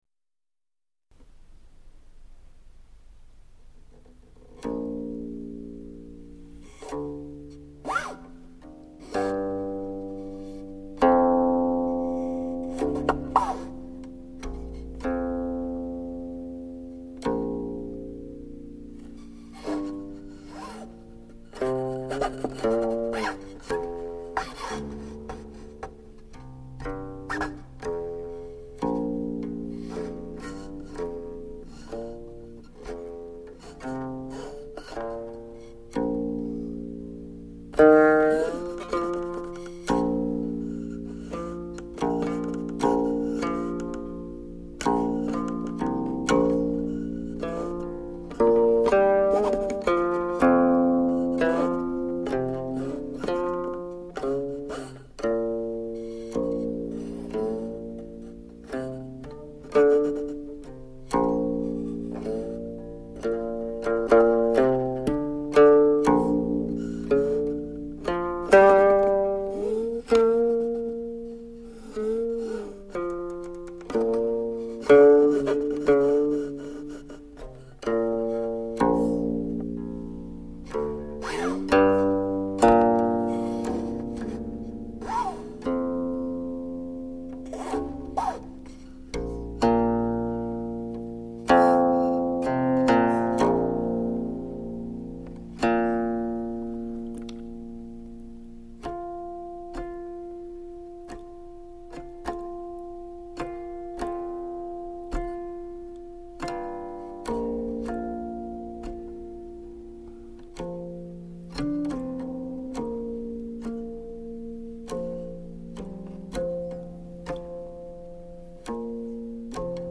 类型：古琴道音乐